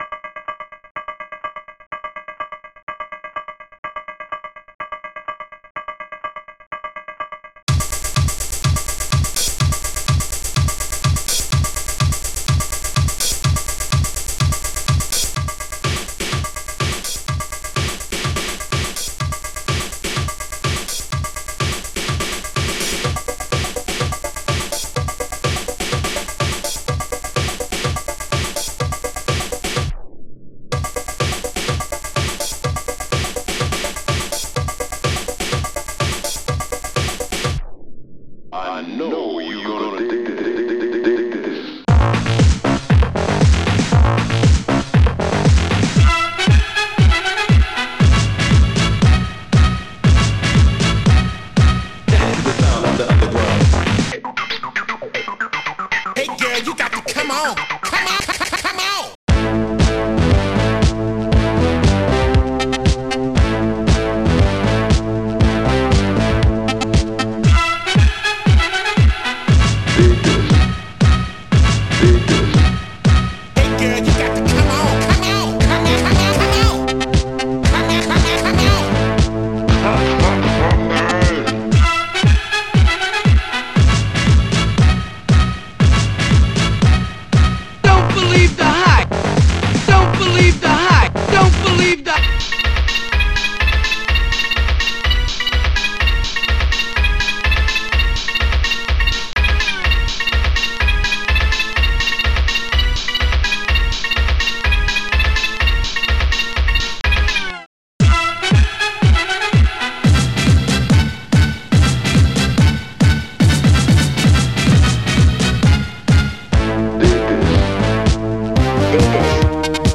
ST-61:PitBassDrm
ST-60:hardsnare